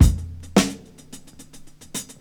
• 108 Bpm Breakbeat G# Key.wav
Free breakbeat - kick tuned to the G# note. Loudest frequency: 1755Hz
108-bpm-breakbeat-g-sharp-key-dsS.wav